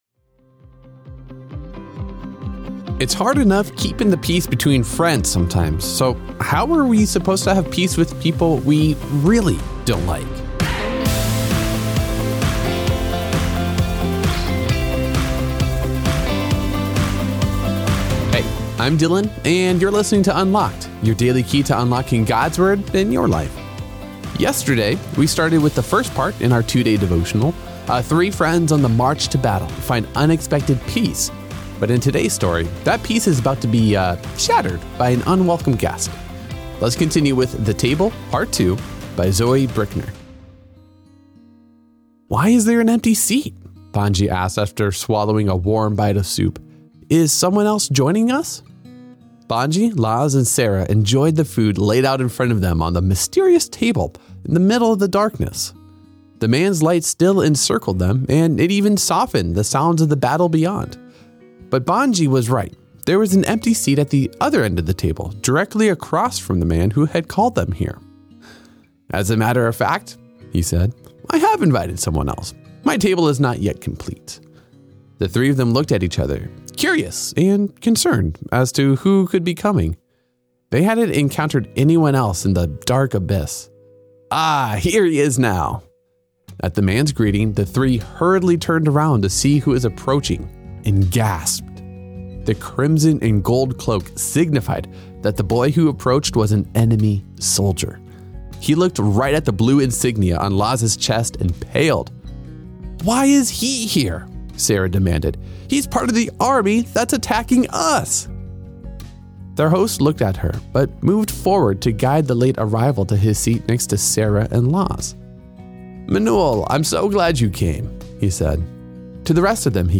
With daily devotions read by our hosts